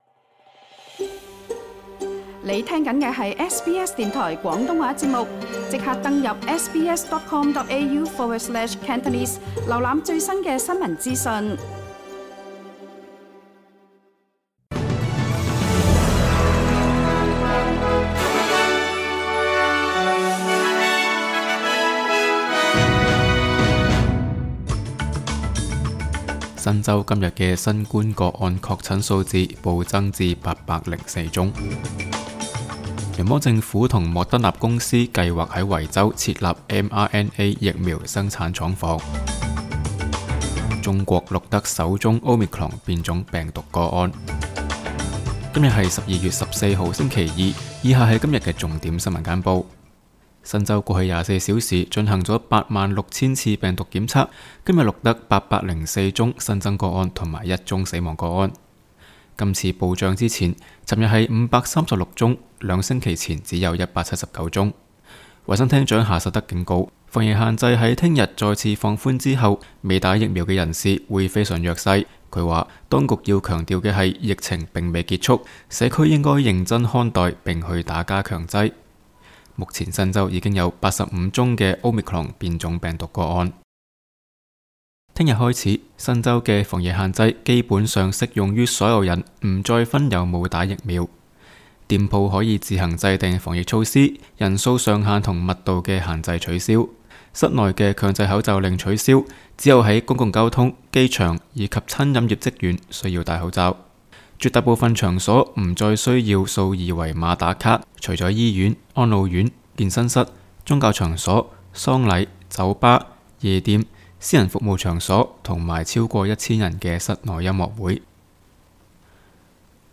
SBS 新聞簡報（12月14日）